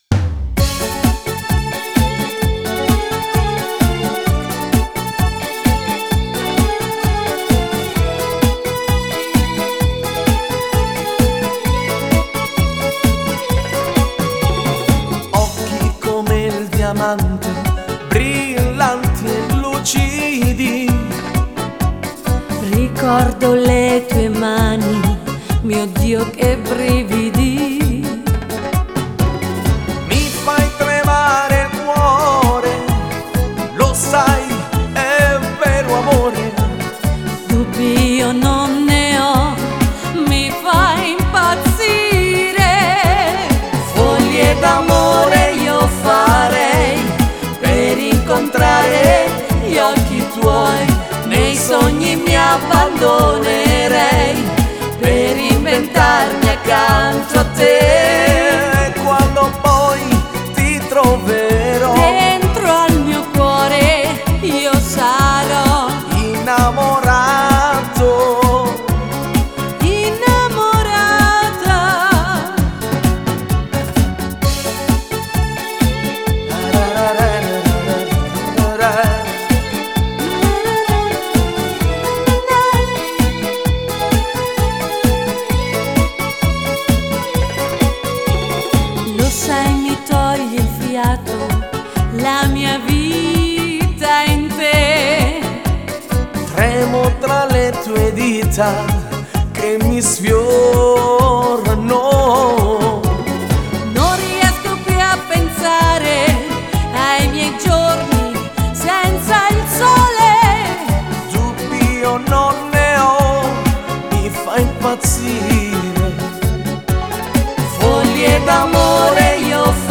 bachata
(Bachata - Duetto uomo/donna)